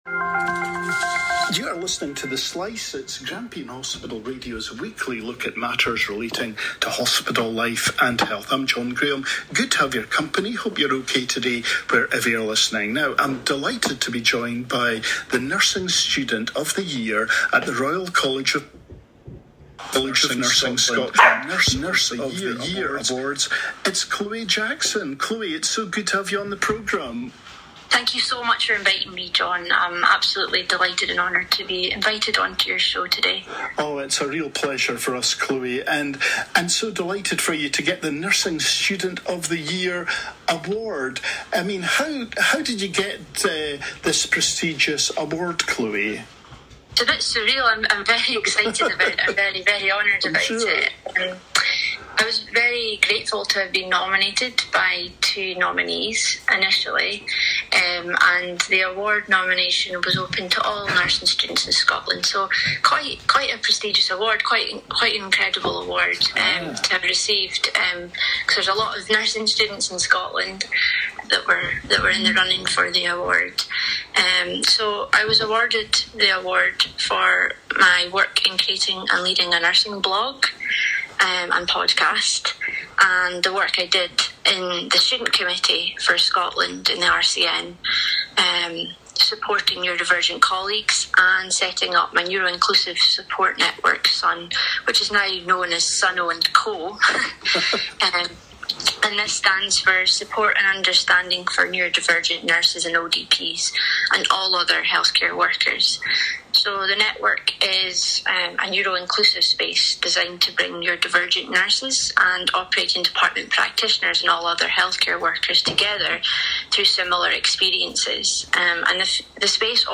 Hospital Radio Interview